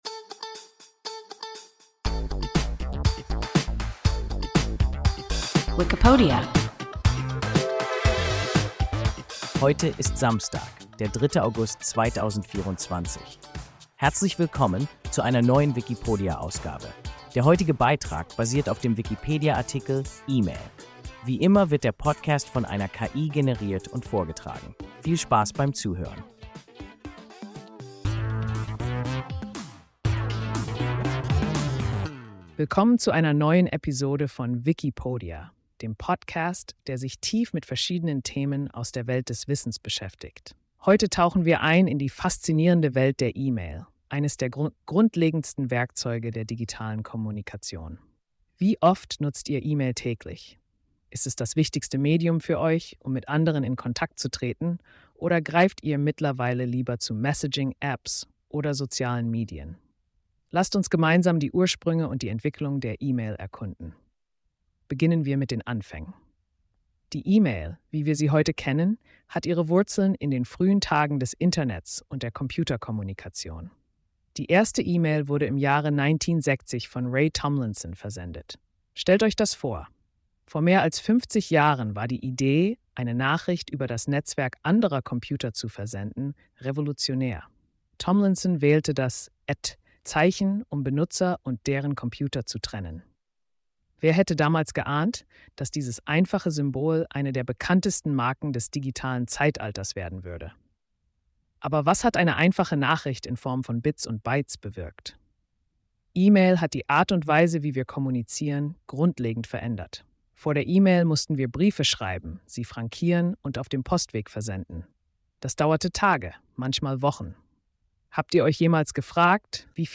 E-Mail – WIKIPODIA – ein KI Podcast